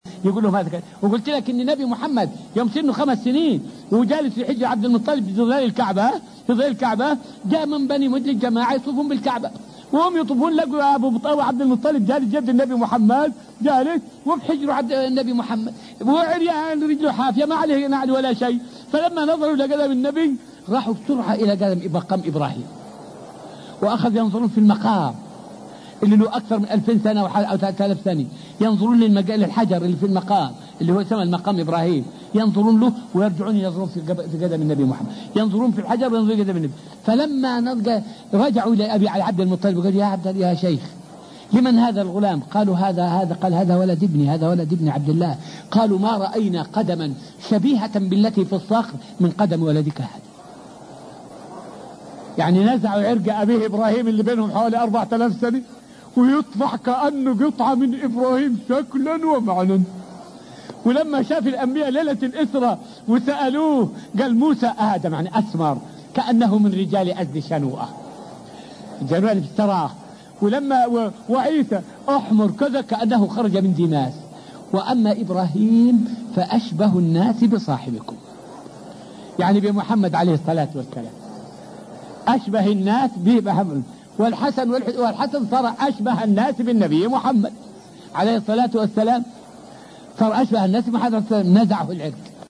فائدة من الدرس الرابع والعشرون من دروس تفسير سورة البقرة والتي ألقيت في المسجد النبوي الشريف حول شبه النبي عليه السلام بإبراهيم عليه السلام.